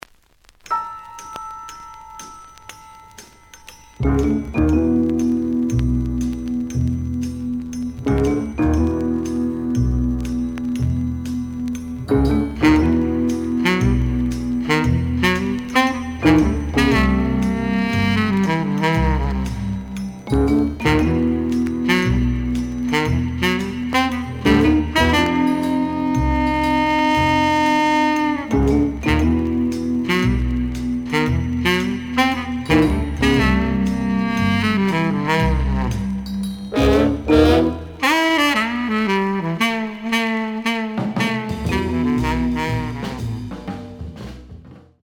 The audio sample is recorded from the actual item.
●Genre: Jazz Other
Slight noise on beginning of A side, but almost good.)